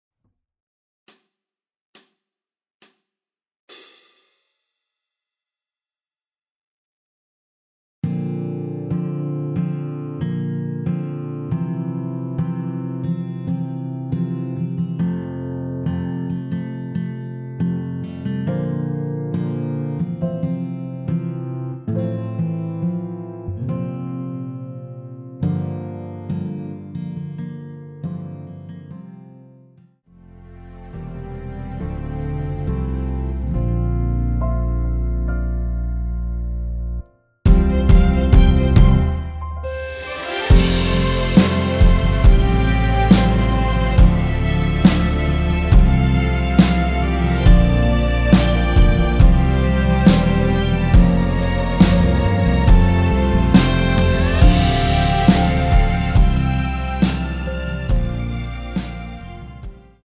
전주 없이 시작하는 곡이라서 시작 카운트 만들어놓았습니다.(미리듣기 확인)
원키에서(-3)내린 (1절앞+후렴)으로 진행되는 MR입니다.
앞부분30초, 뒷부분30초씩 편집해서 올려 드리고 있습니다.
중간에 음이 끈어지고 다시 나오는 이유는